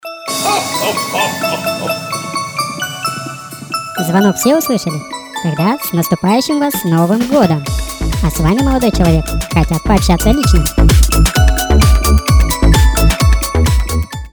• Качество: 320, Stereo
веселые
колокольчики
мужские
голосовые
смех
Бой Курантов